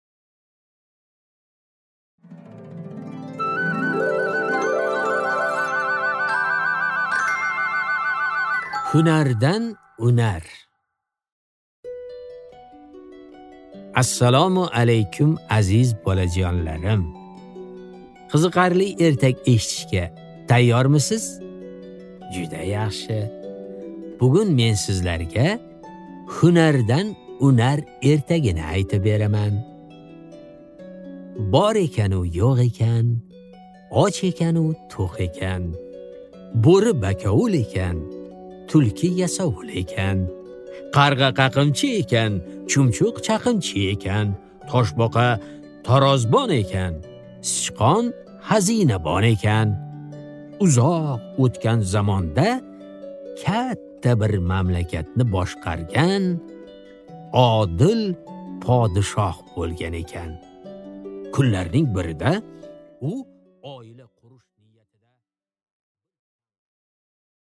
Аудиокнига Hunardan unar